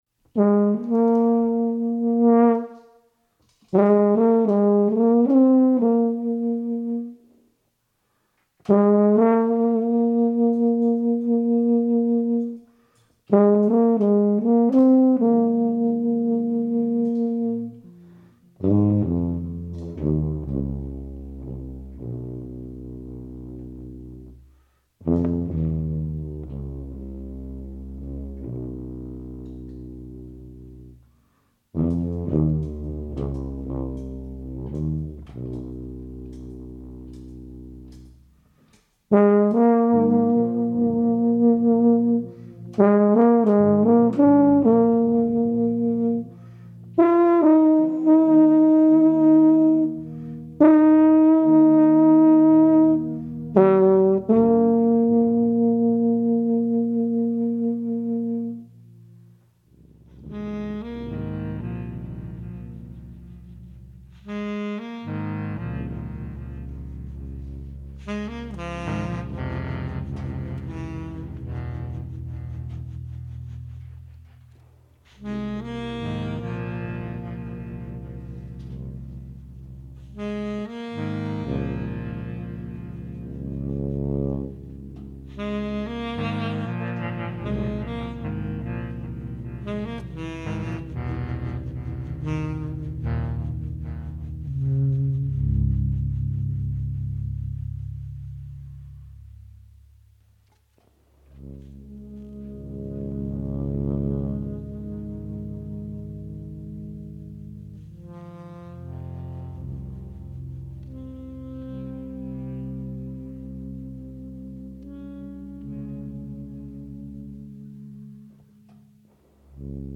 Recorded live at ABC NoRio, Lower East Side, Manhattan
tuba
alto saxophone, electronics
Stereo (722 / Pro Tools)